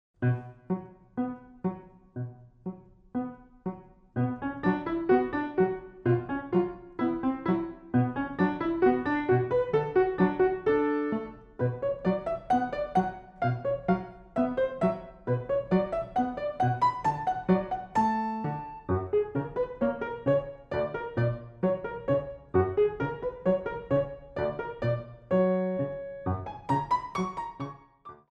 Piano music for the dance studio
Pirouettes 1
delicate version